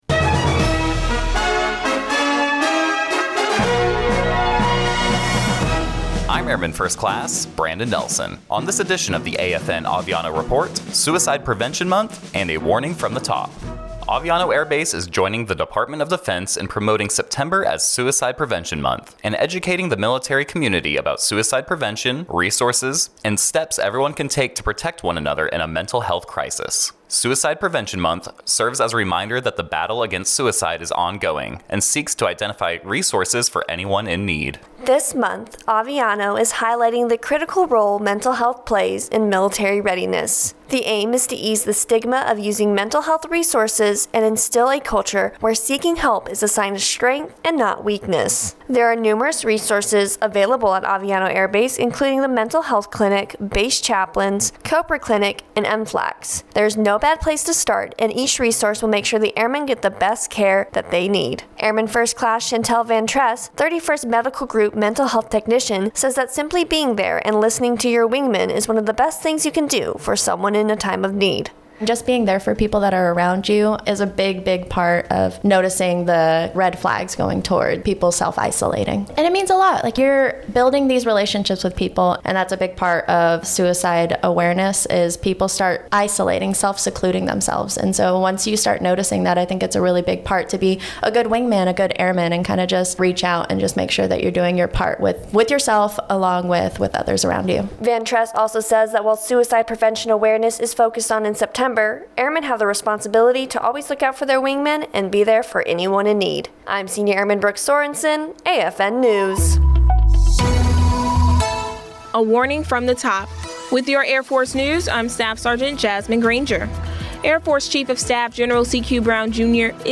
American Forces Network (AFN) Aviano radio news reports on National Suicide Prevention month and mental health resources available to Airmen at Aviano Air Base.